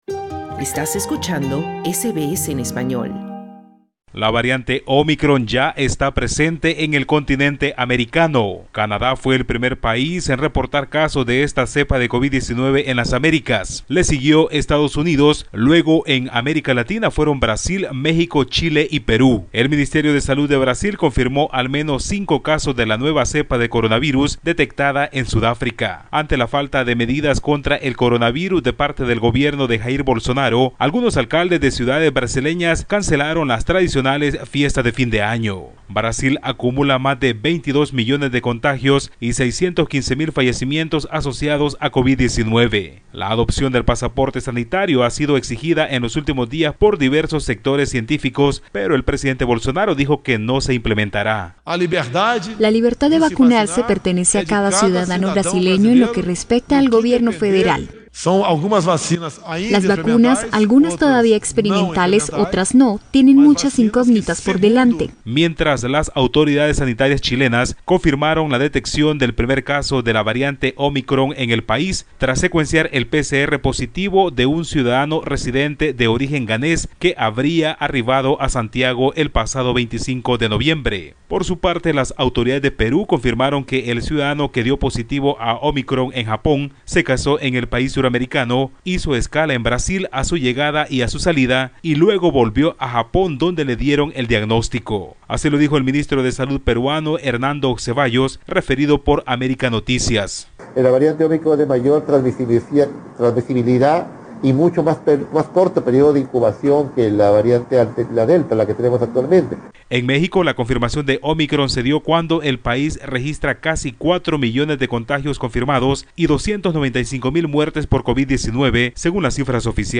Canadá fue el primer país en reportar casos de esta cepa de COVID-19, después siguió Estados Unidos. Además, se han reportado casos en Brasil, México, Chile y una persona que estuvo en Perú. Escucha el informe del corresponsal de SBS Spanish en la región